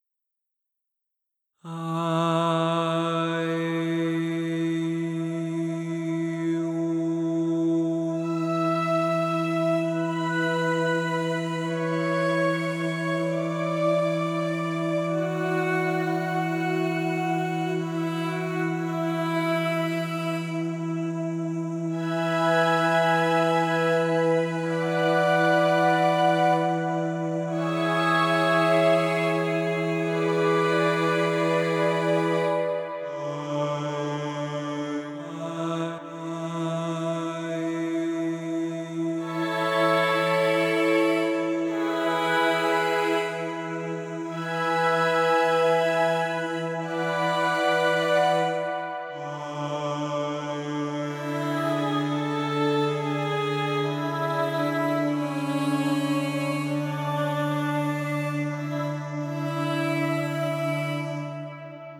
Diverse Instrumente wurden so verändert, das sie an Stimmen erinnern.
Hier zwei Beispiele der Augument Voices. mit verschiedenen Instrumenten.
arturia-augments-voices-1.mp3